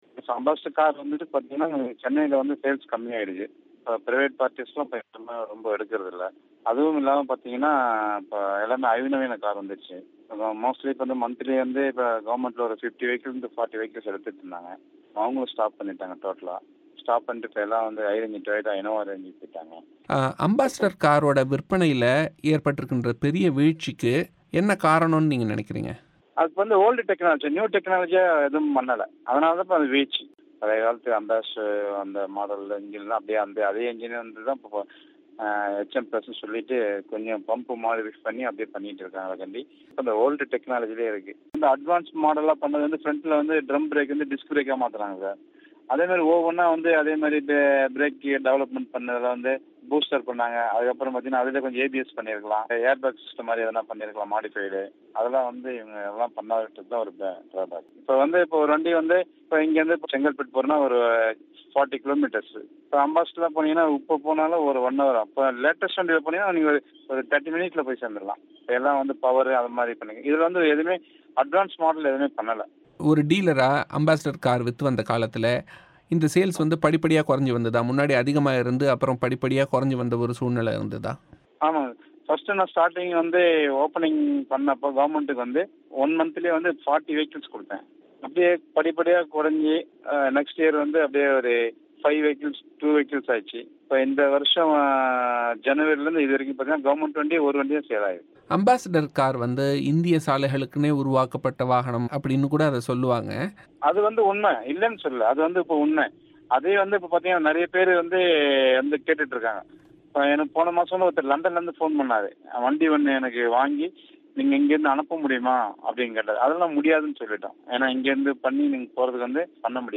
செவ்வி.